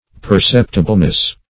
[1913 Webster] -- Per*cep"ti*ble*ness, n. --